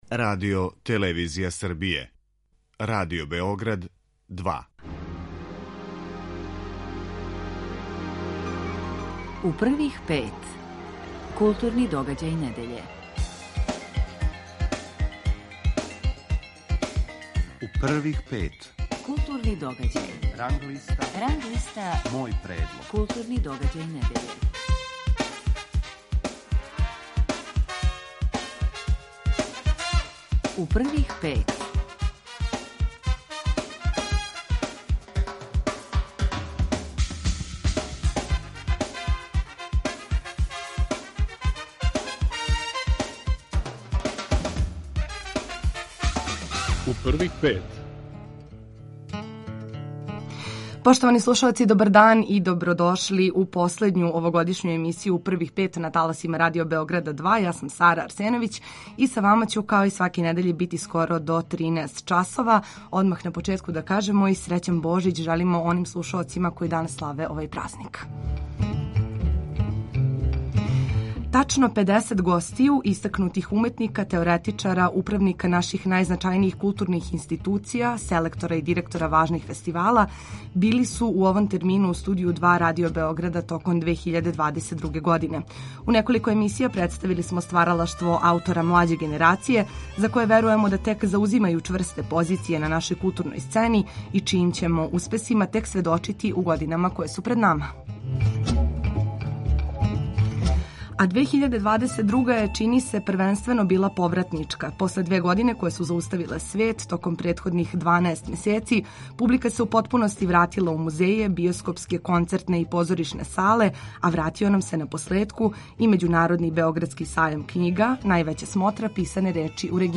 У емисији У првих пет сваке недеље од 11 сати, уз централног госта емисије, слушамо осврте критичара и критичарки на оно најважније што се догађало у нашем културном животу.